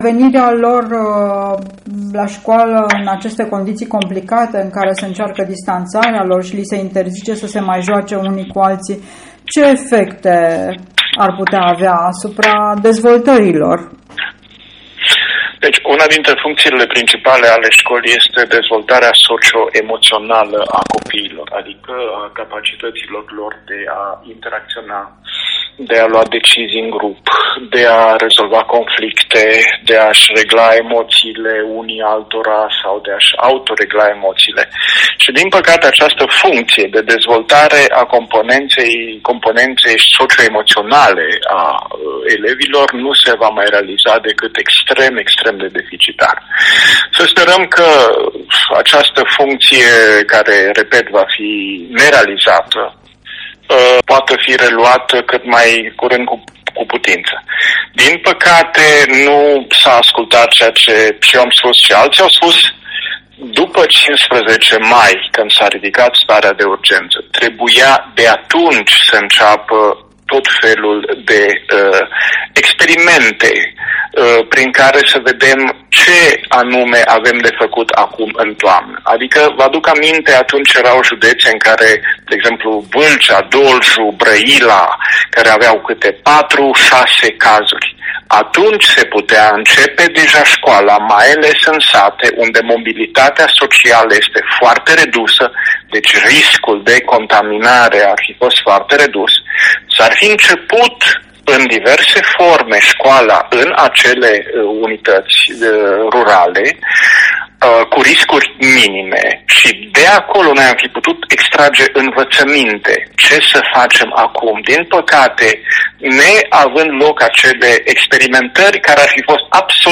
Interviul cu fostul ministru român al educației, Mircea Miclea